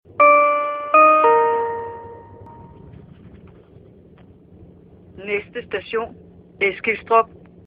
Højttalerudkald - "Næste station.."